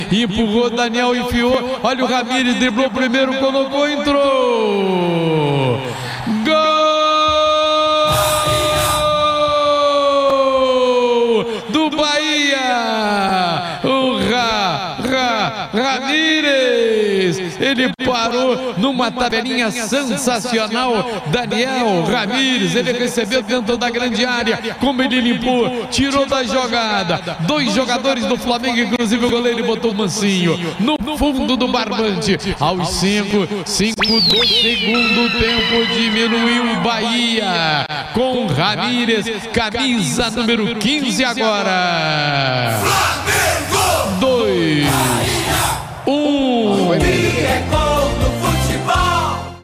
Ouça os gols da vitória emocionante do Flamengo em cima do Bahia, na voz de José Carlos Araújo